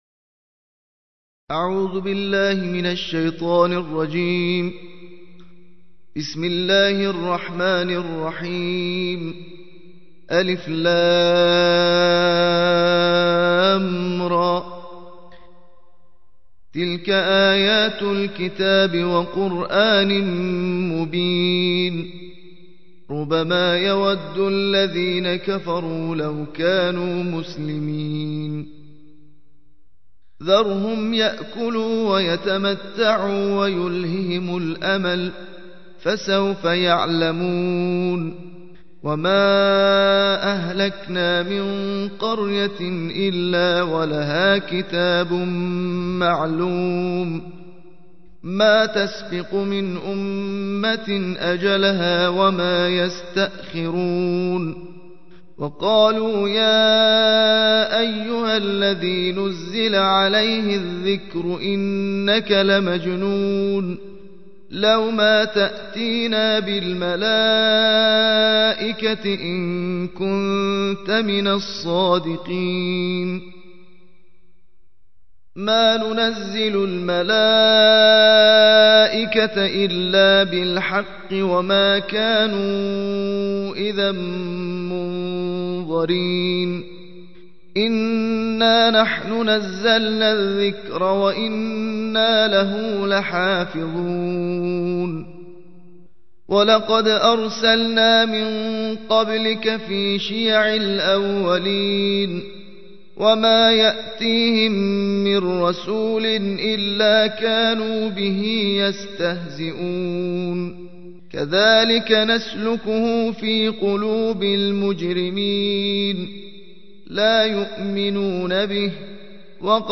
الجزء الرابع عشر / القارئ